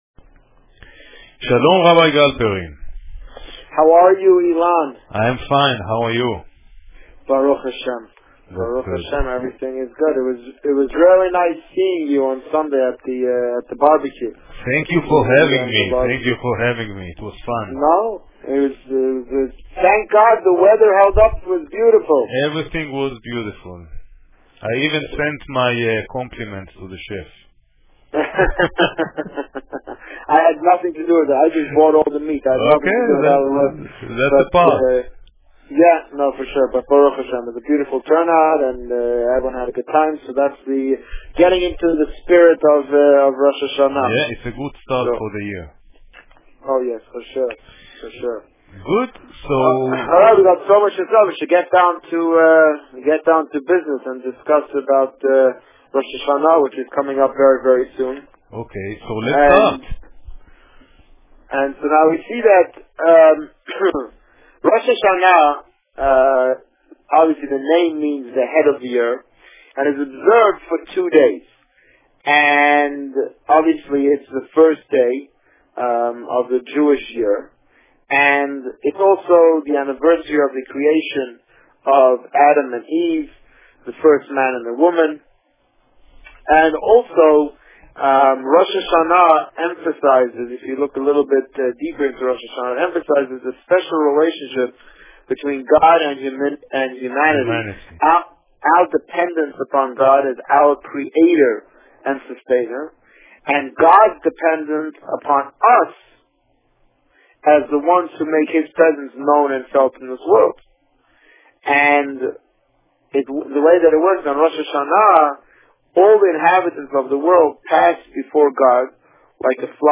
The Rabbi on Radio
Rosh Hashana 2011 Published: 22 September 2011 | Written by Administrator On September 22, 2011, the Rabbi spoke about the annual picnic, preparations for the upcoming holidays, and Rosh Hashana in particular. Listen to the interview here .